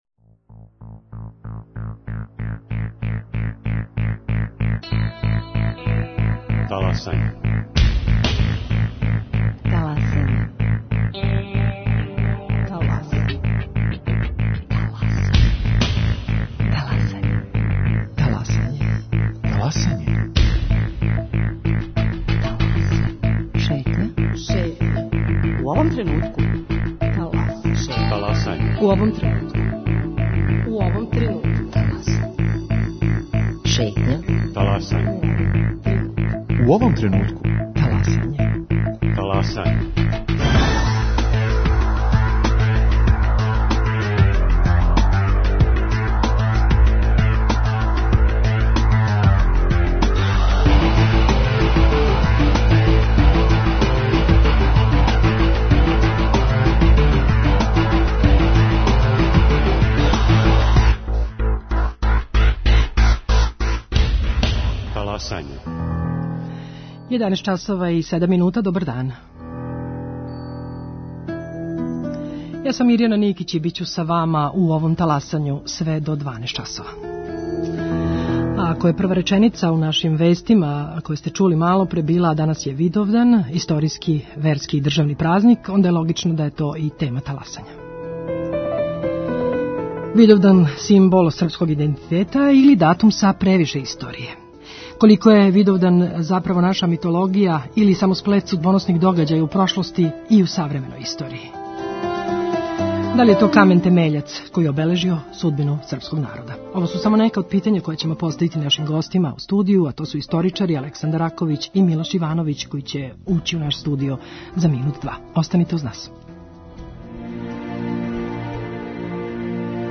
Гости: историчари